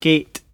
[gayt]